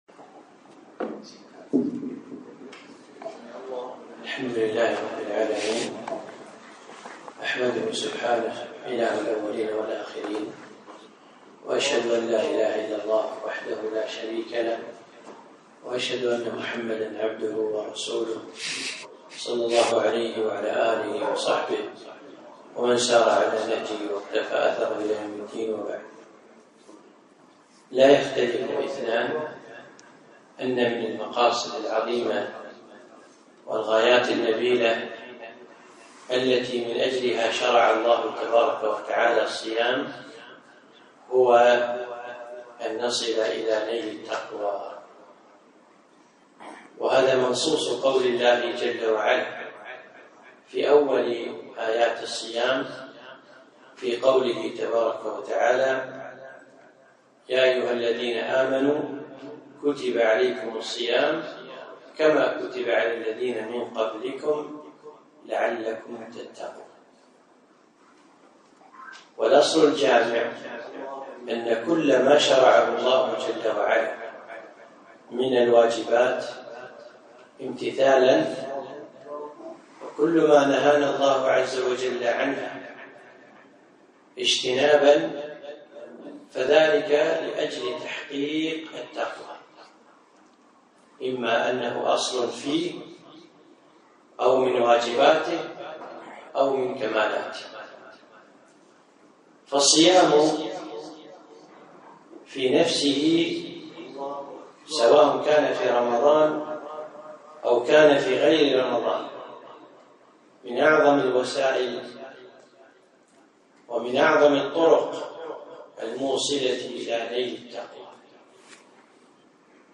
محاضرة - صيامنا وتحقيق التقوى